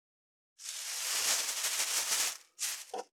638コンビニ袋,ゴミ袋,スーパーの袋,袋,買い出しの音,ゴミ出しの音,袋を運ぶ音,
効果音